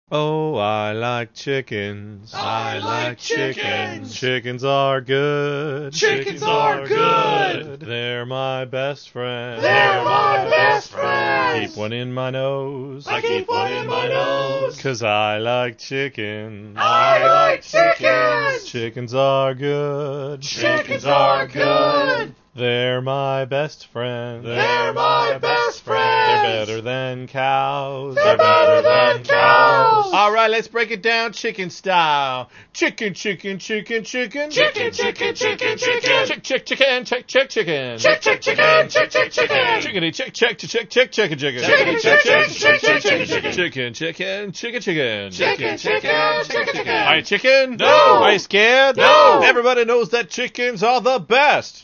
They're in mp3 format, and the quality is, uh, not excellent.